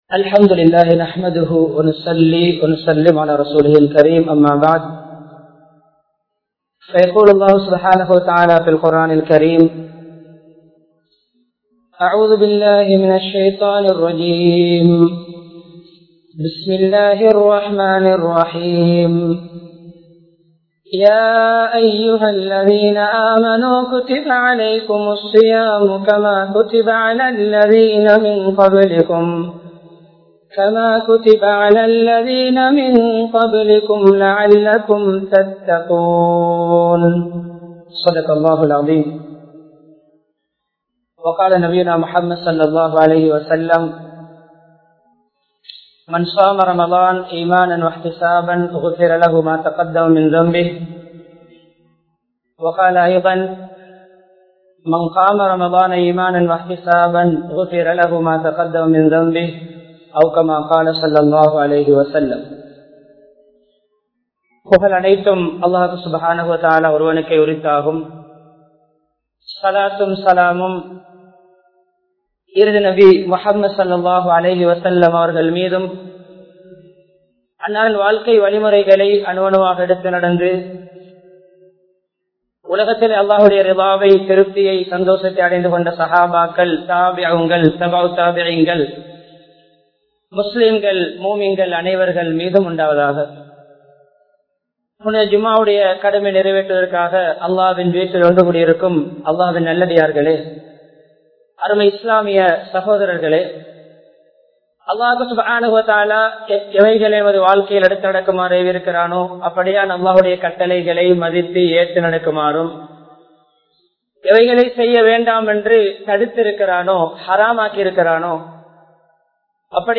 Ramalanil Naam Seiya Veandiyavaihal (ரமழானில் நாம் செய்ய வேண்டியவைகள் | Audio Bayans | All Ceylon Muslim Youth Community | Addalaichenai